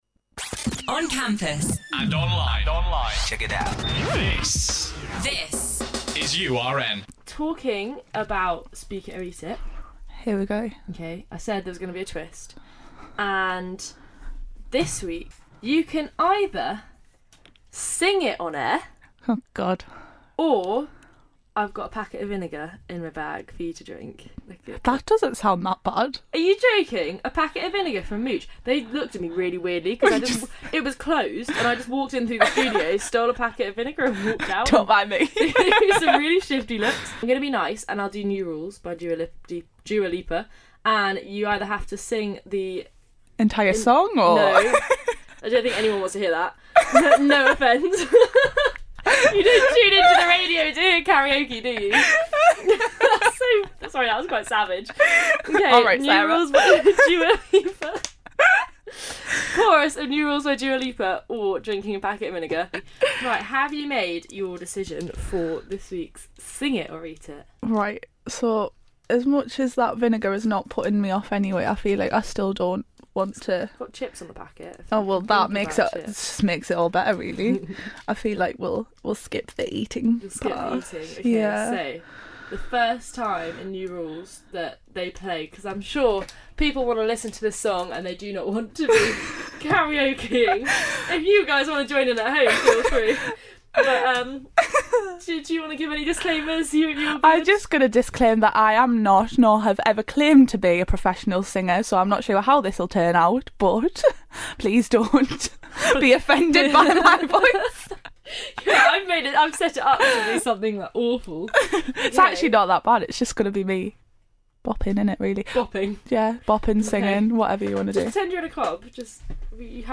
performing a musical rendition live on air